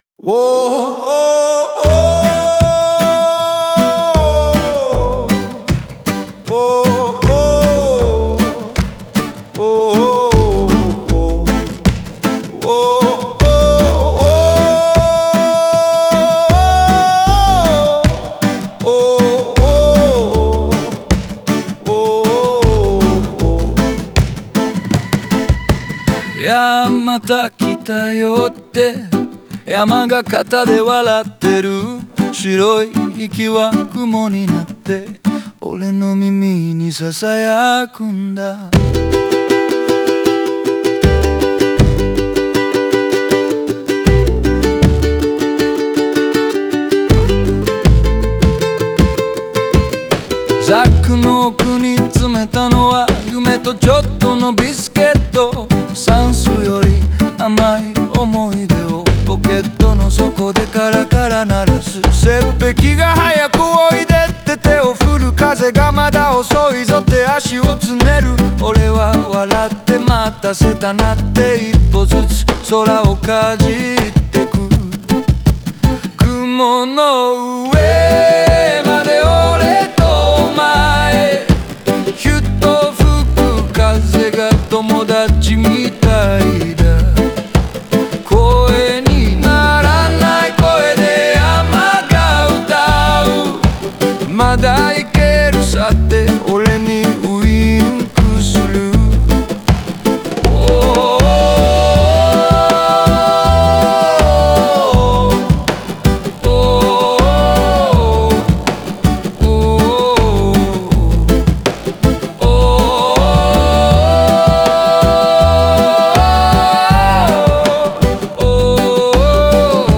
オリジナル曲♪
この歌詞は、高山を舞台にした挑戦と内面の対話を、陽気なアイランドビートに乗せて描いています。